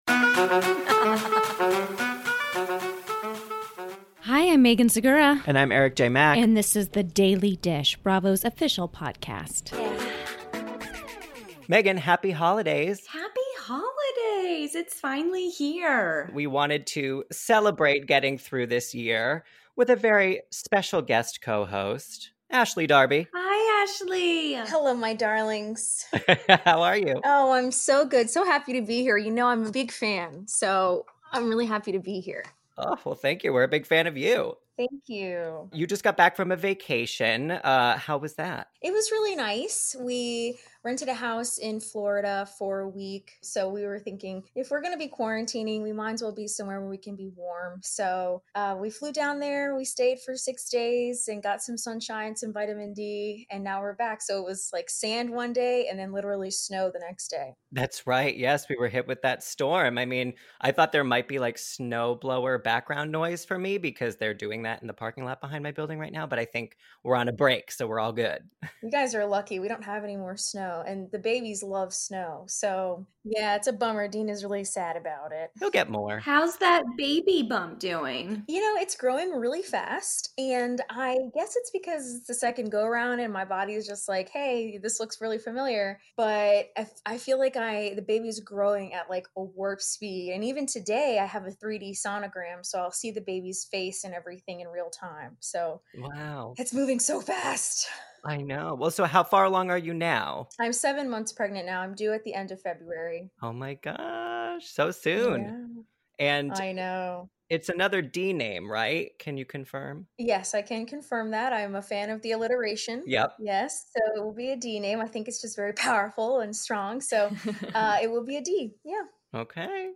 As a holiday treat, Ashley Darby joins us as guest co-host to unpack the major Real Housewives of Potomac Reunion Parts 1 and 2. Ashley explains why Michael Darby did not attend this year and reveals whether she saw the inside of Monique’s “receipt binder,” plus shares where she and Candiace (and Karen) stand today.
Enjoy some messages from your favorite Bravolebs.